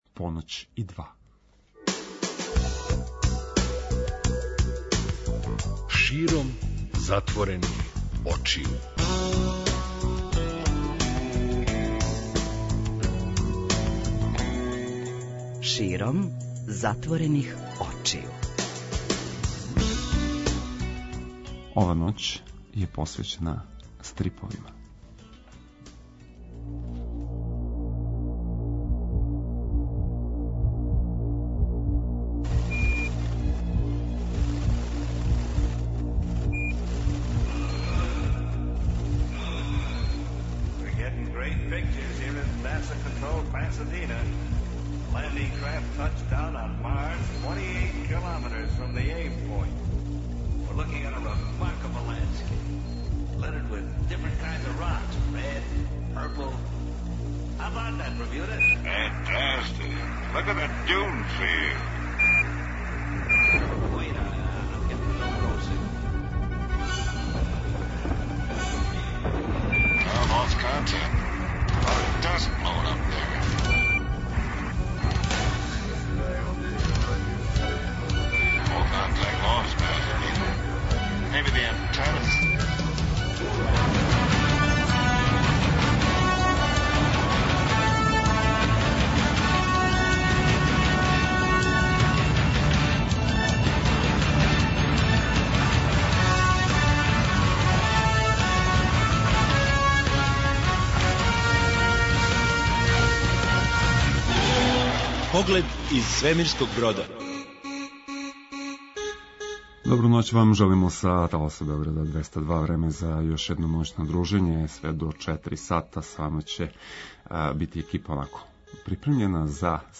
Своје штандове имали су стрип-издавачи, али и свој простор за одржавање промоција и трибина!